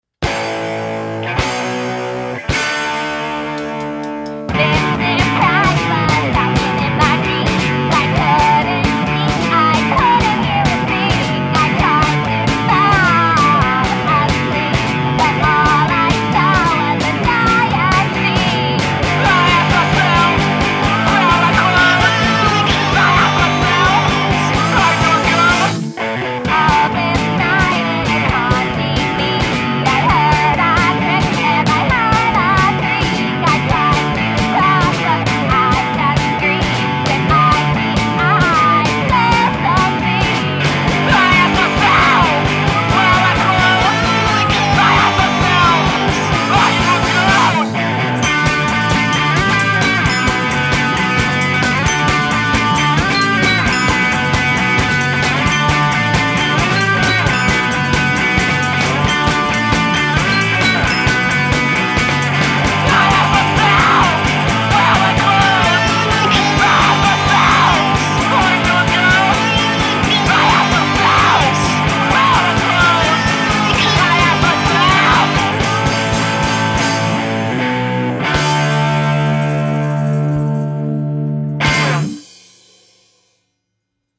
Genre: Gross Punk